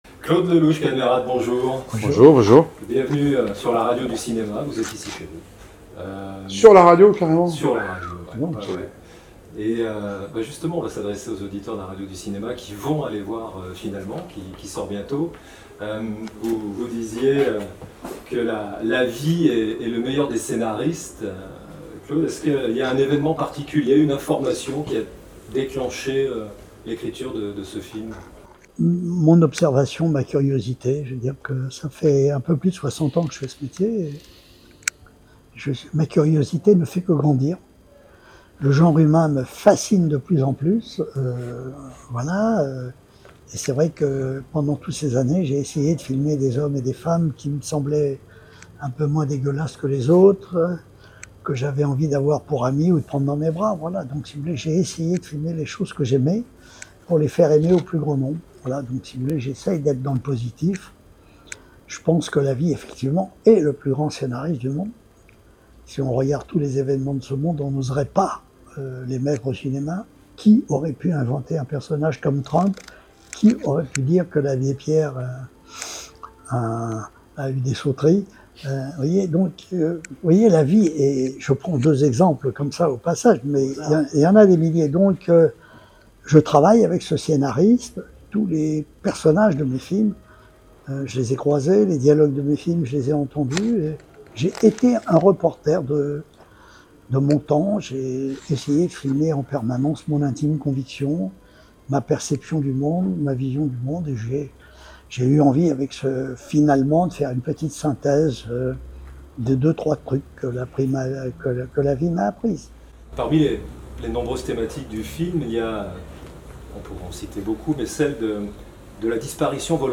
Claude Lelouch et Kad Merad : La vie, ce scénariste de génie – Entretien autour de "Finalement" au cinéma le 13 novembre 2024